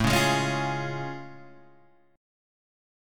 A Augmented Major 7th